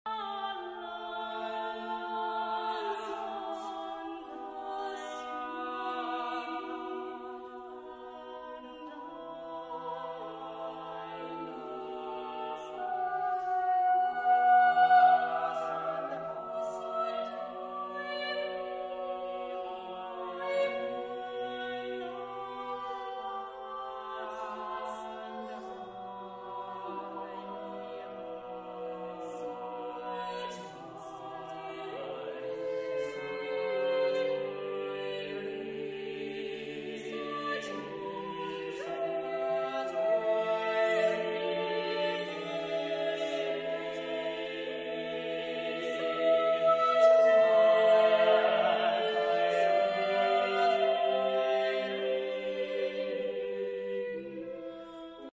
Madrigal
Solo Voice Ensemble Singing